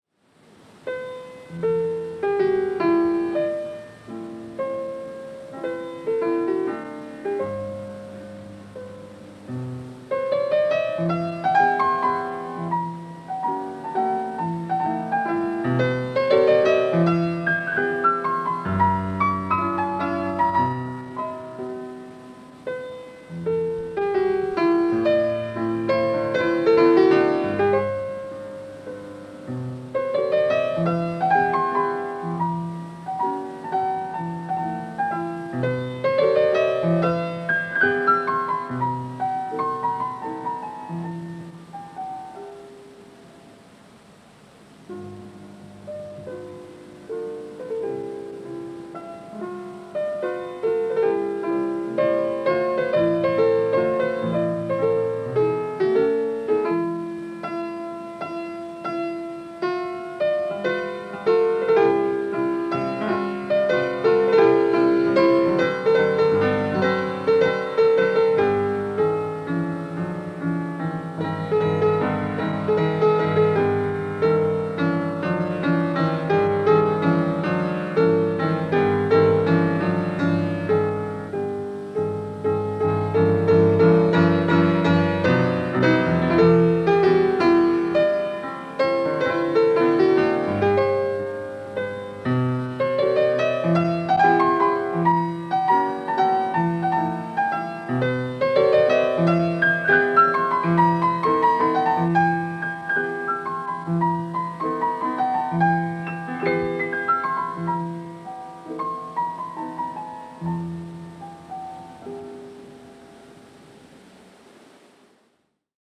en vivo, año 1982
piano
en al bemol mayor